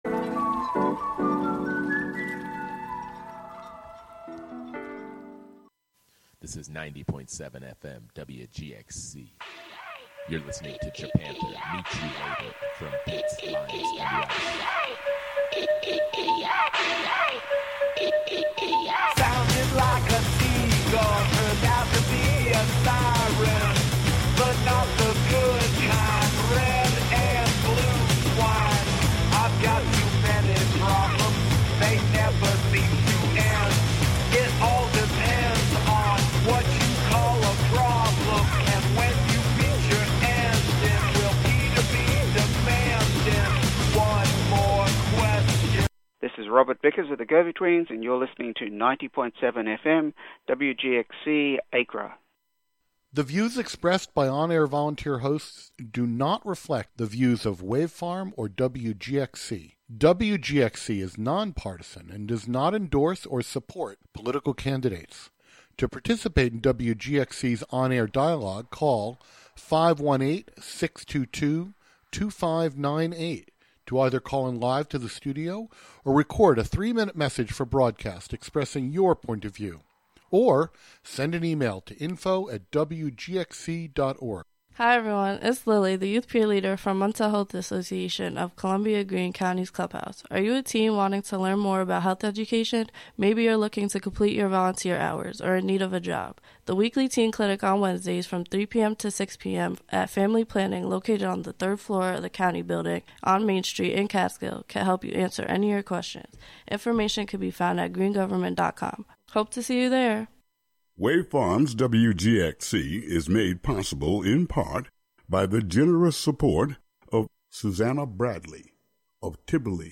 In this broadcast we listen to different things singing and making music together, the wind sings with the birds, synth tape loops sing with birds, someone sings with the earth, and more.
To extend this idea into practice, other non-human forms and systems will co-host the show, speak, and sing.